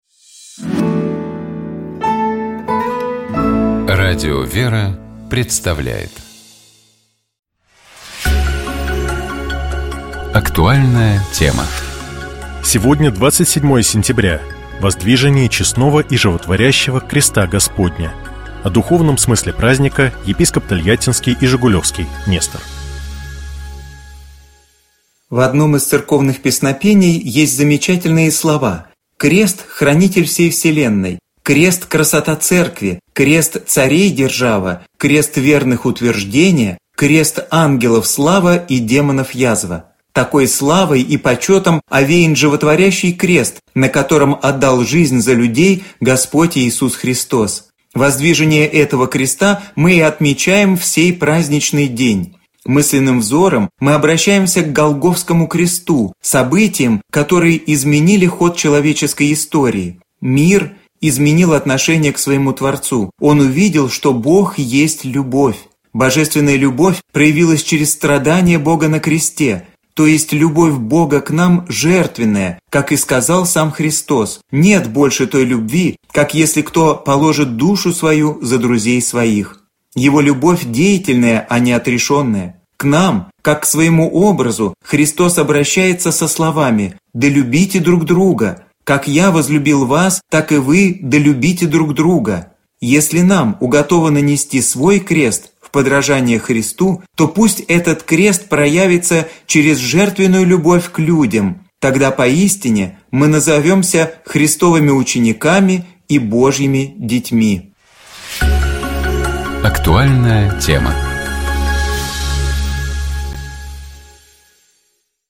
Псалом 118. Богослужебные чтения - Радио ВЕРА